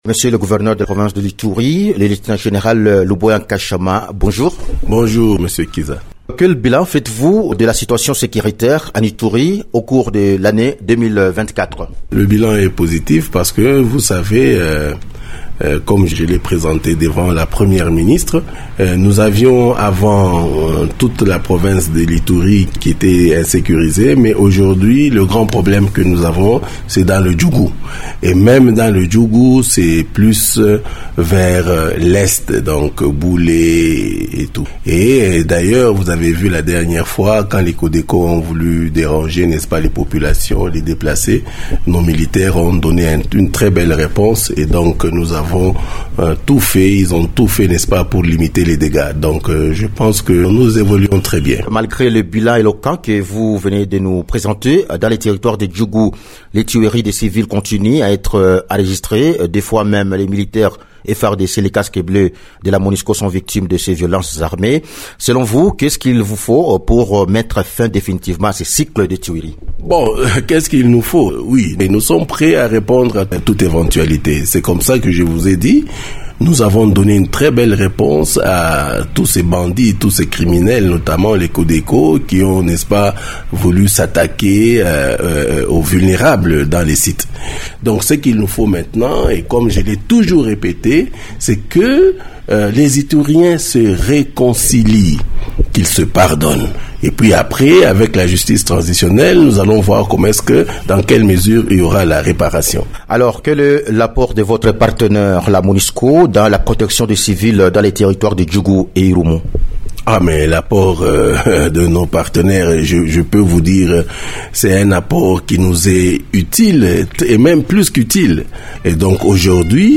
Invité de Radio Okapi, il reconnait que seul le territoire de Djugu qui reste le ventre mou de cette province :